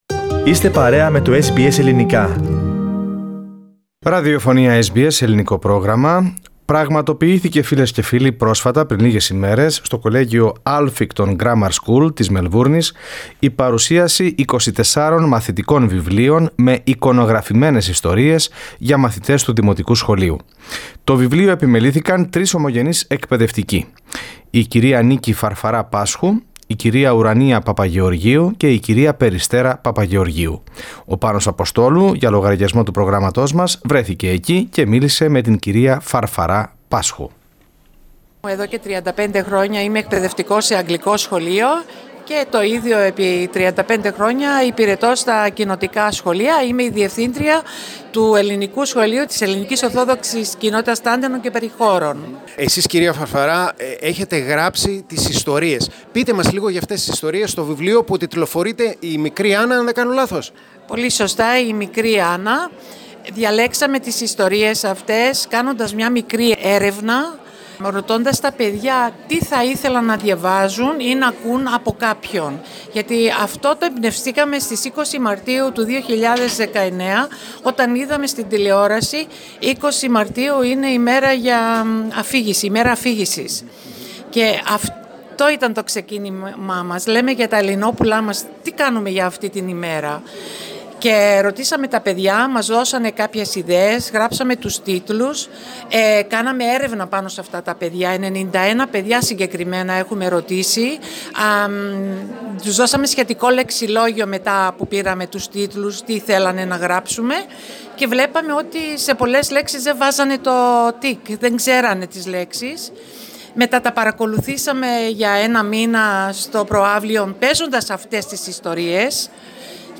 From the book launch. Source: SBS Greek